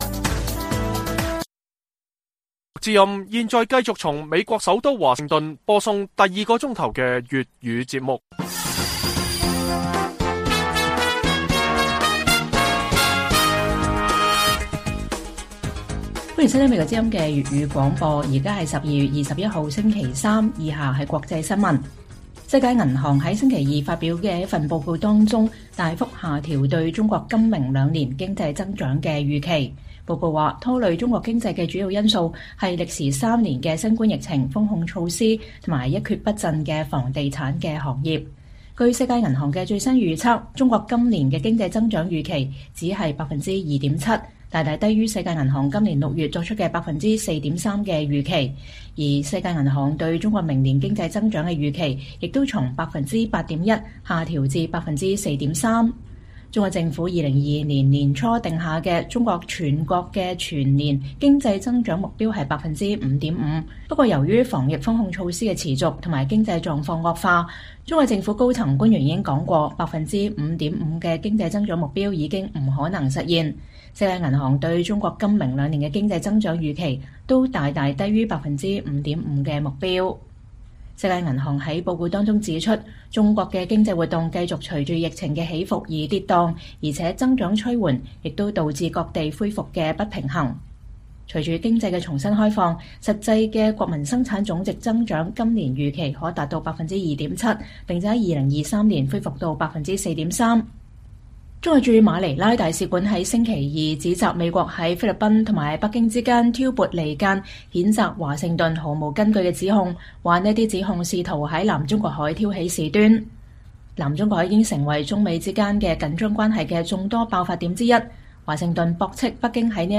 粵語新聞 晚上10-11點: 世界銀行大幅下調中國今明兩年經濟增長預期